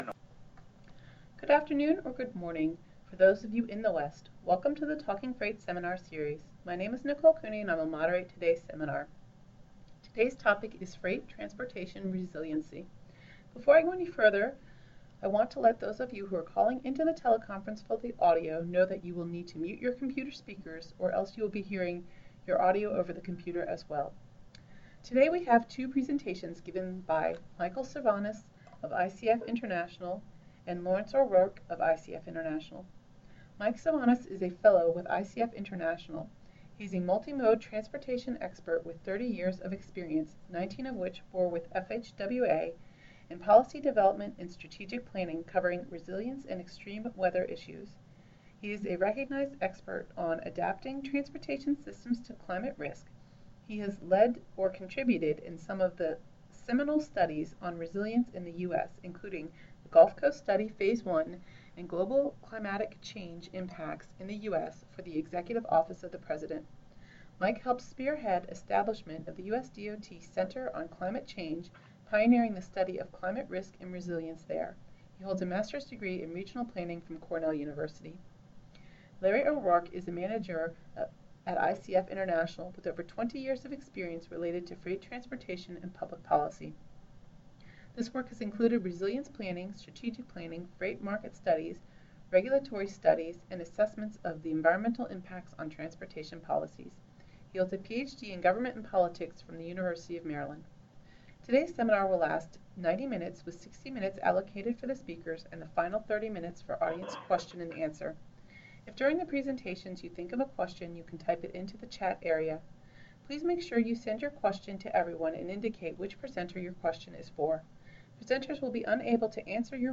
Today we’ll have two presentations, given by:
Today’s seminar will last 90 minutes, with 60 minutes allocated for the speakers, and the final 30 minutes for audience Question and Answer.